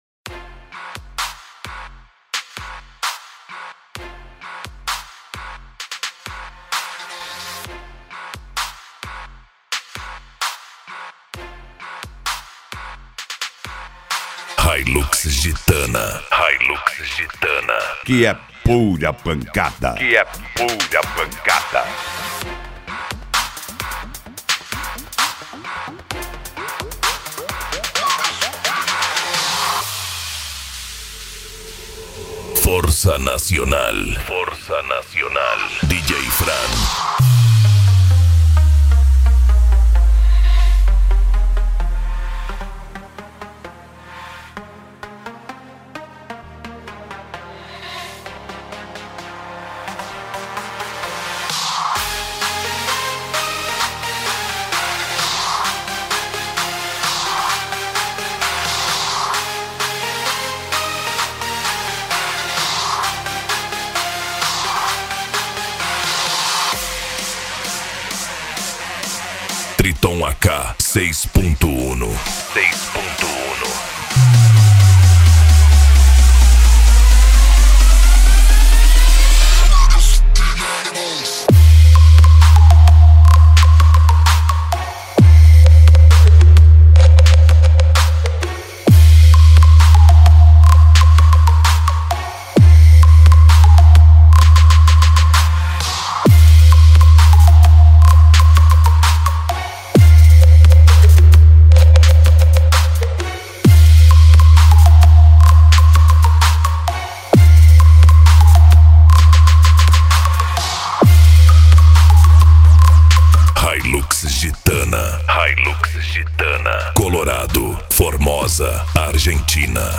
Bass
PANCADÃO